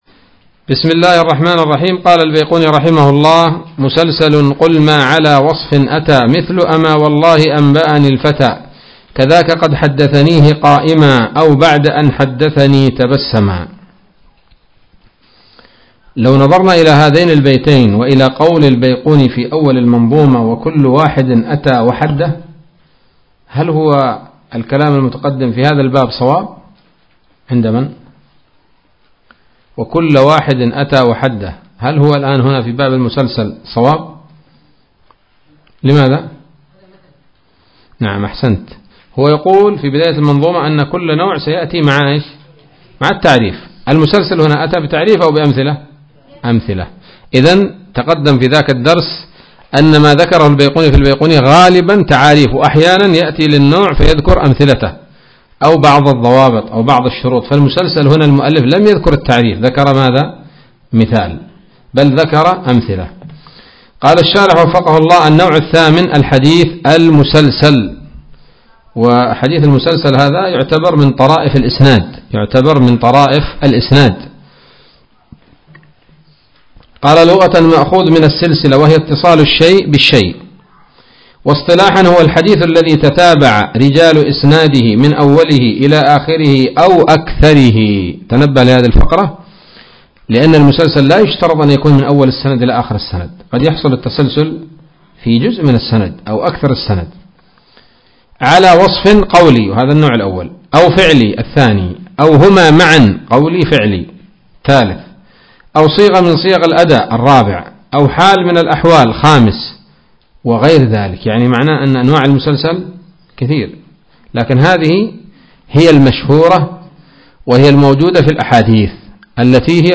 الدرس الرابع عشر من الفتوحات القيومية في شرح البيقونية [1444هـ]